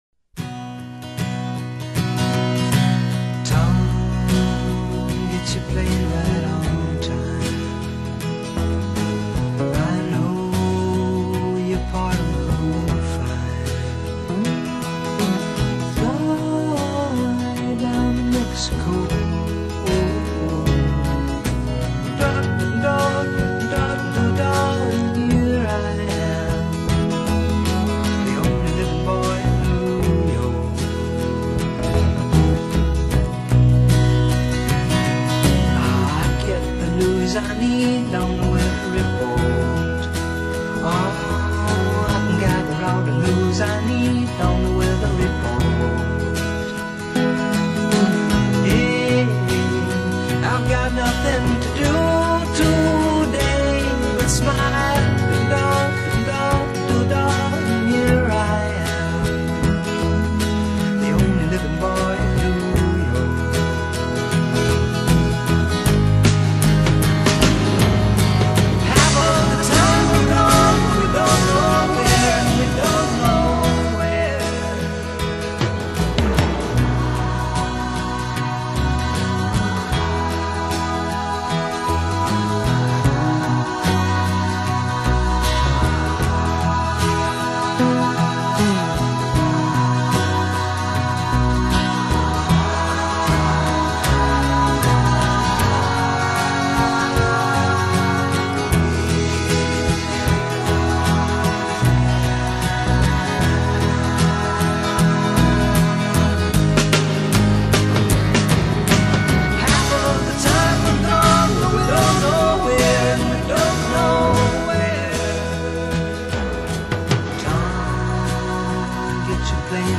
It’s a brilliant song, simply strummed and softly sung.